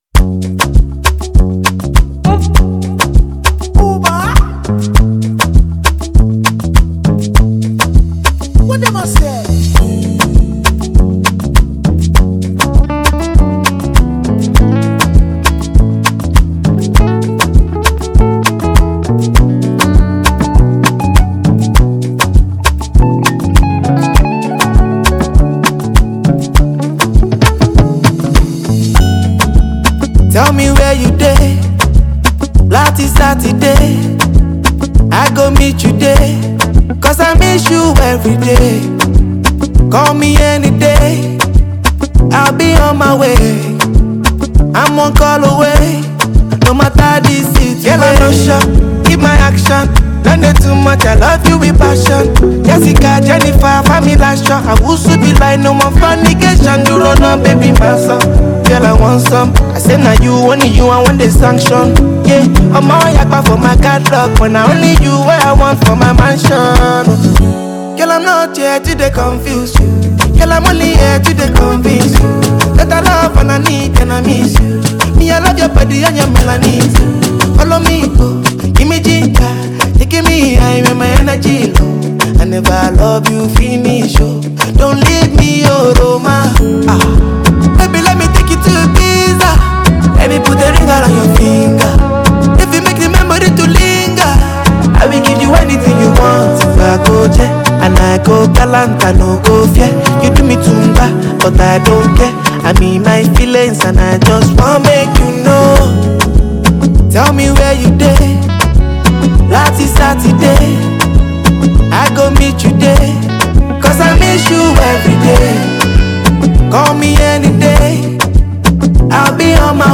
Genre: Afrobeats